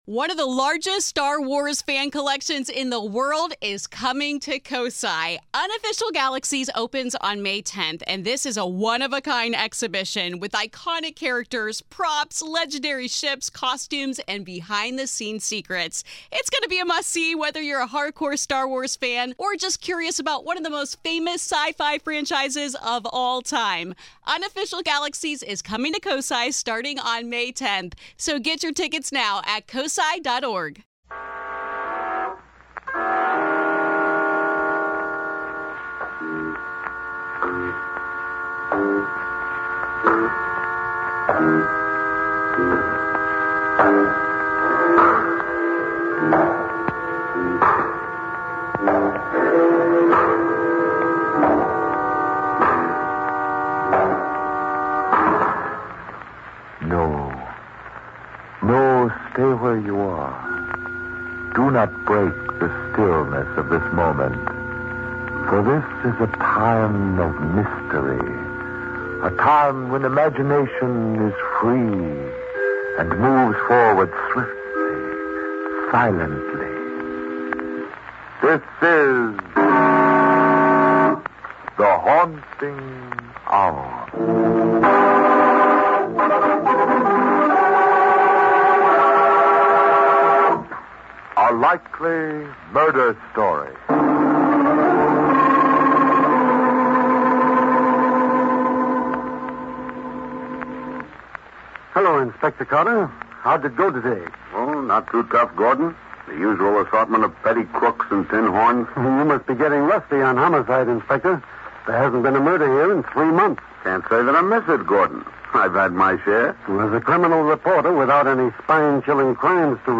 On this episode of the Old Time Radiocast we present you with two stories from the classic radio program The Haunting Hour!